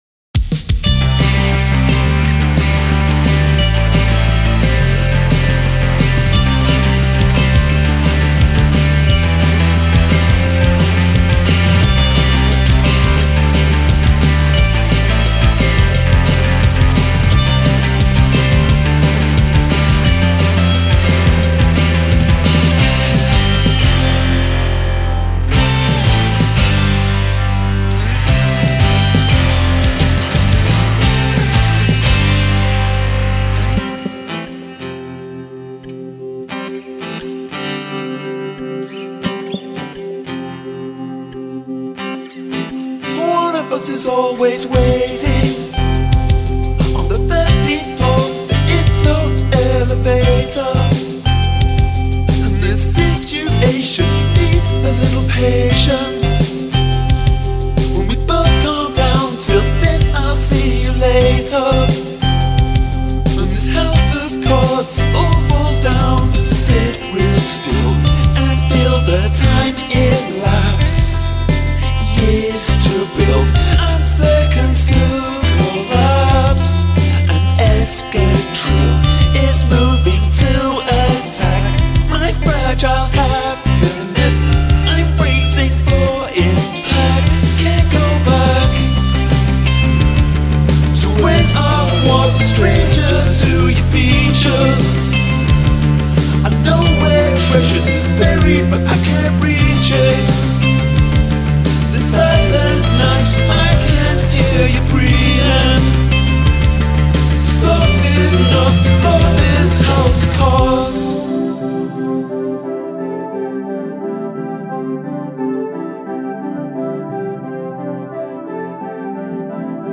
This kind of bouncy pop music is right up my alley.
The breakdown is very effective.
i like the rhythmic guitar that comes in partway.
Double vocal works.